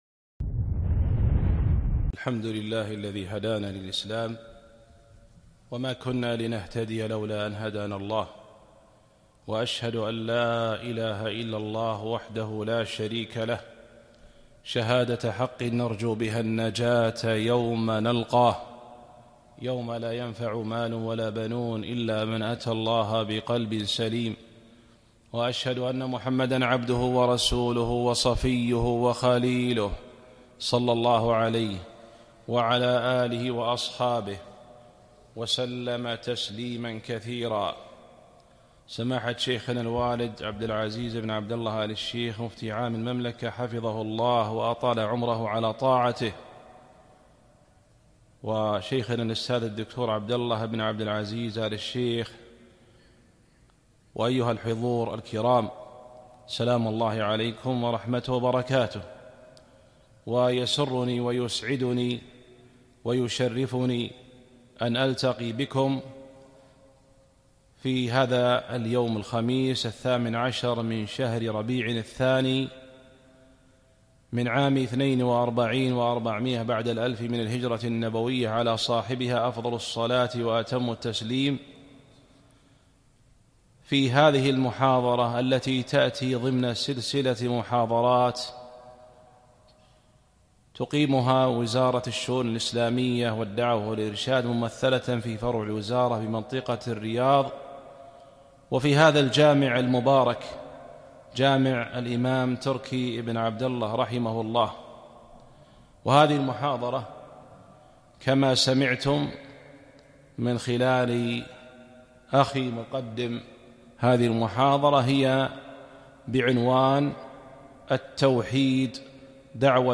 محاضرة - التوحيد دعوة الأنبياء والمرسلين